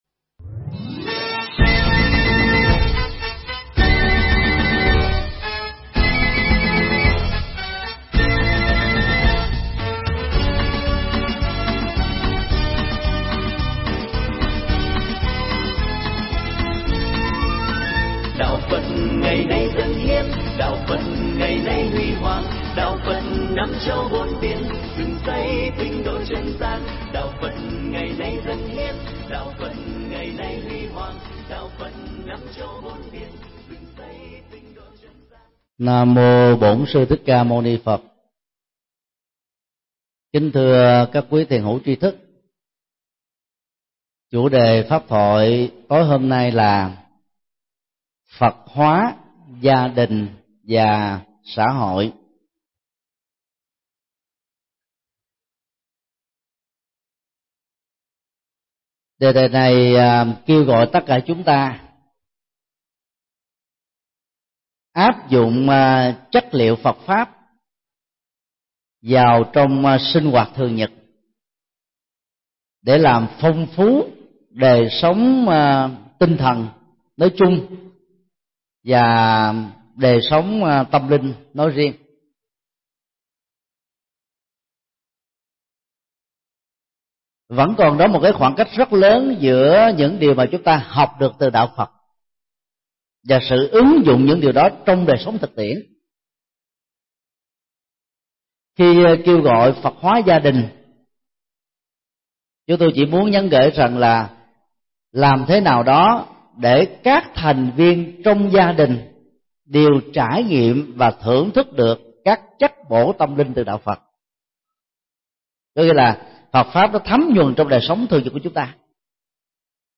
Thuyết Giảng
Giảng tại chùa Giác Ngộ